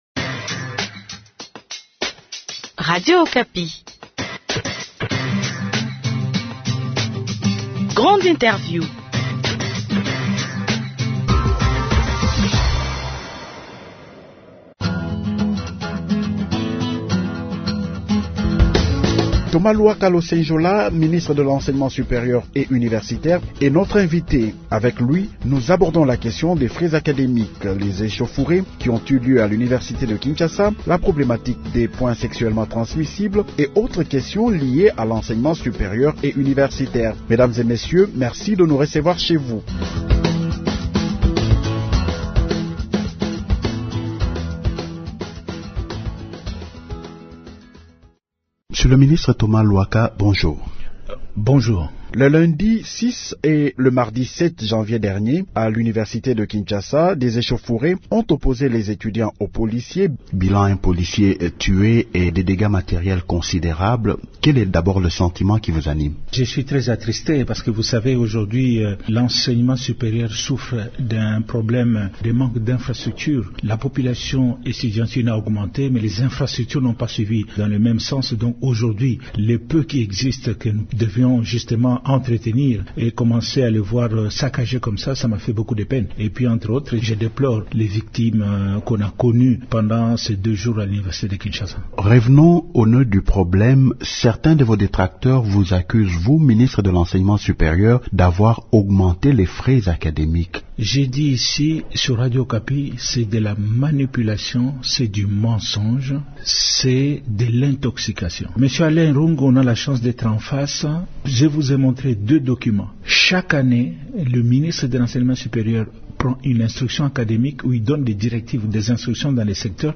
Grande Interview reçoit Thomas Luhaka Losenjola, ministre de l’enseignement supérieur et universitaire.